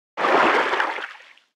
Sfx_creature_seamonkeybaby_swim_slow_03.ogg